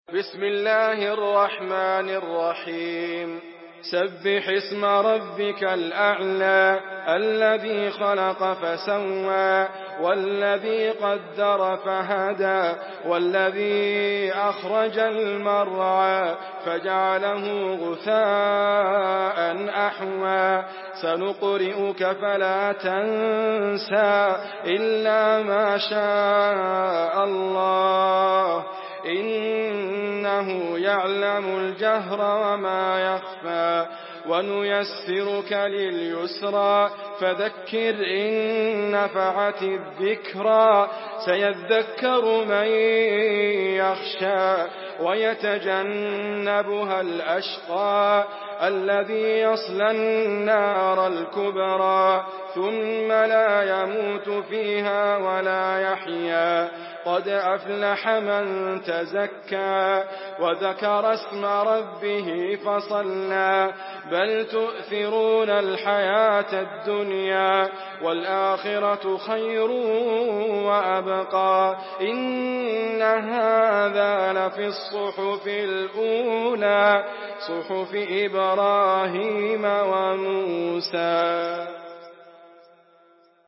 Surah Al-Ala MP3 in the Voice of Idriss Abkar in Hafs Narration
Murattal Hafs An Asim